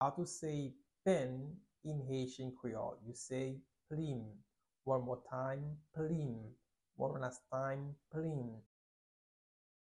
Pronunciation:
15.How-to-say-Pen-in-haitian-creole-–-Plim-pronunciation-1-1.mp3